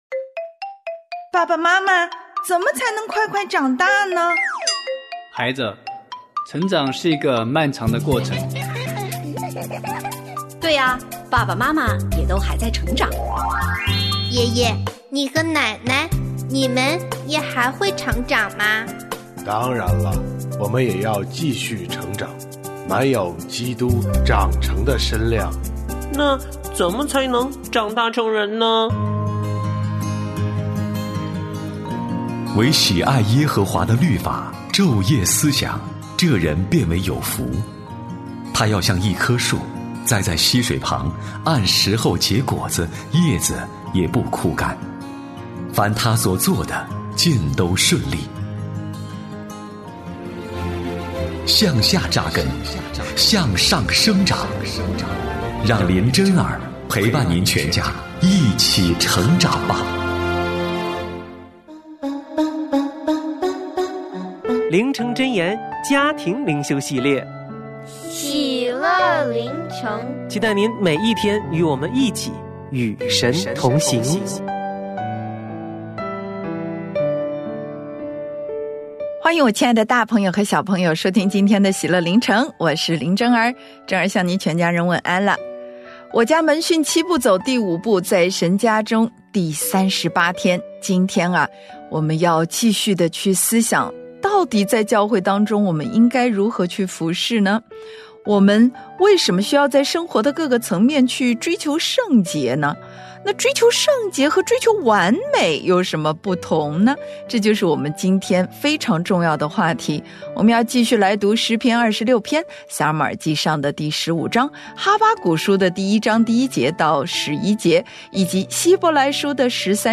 我家剧场：圣经广播剧（118）上帝对所罗门王发怒；上帝兴起所罗门王的仇敌